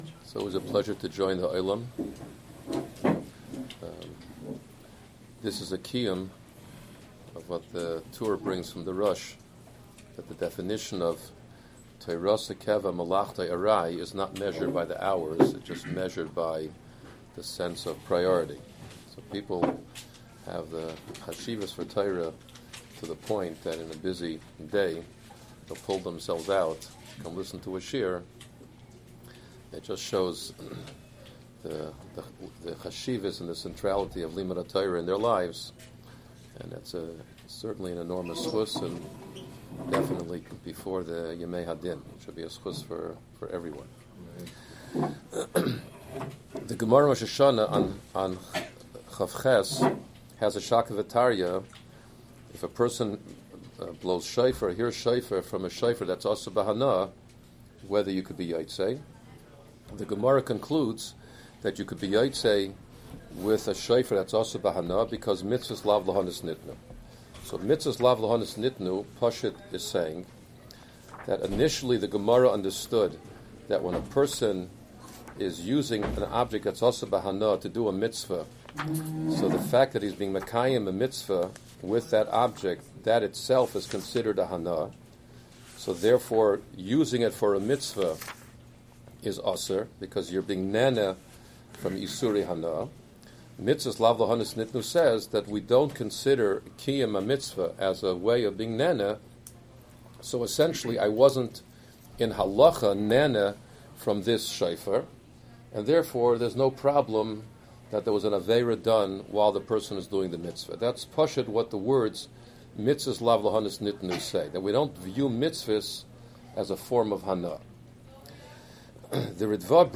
Lunch and Learn – Pikesville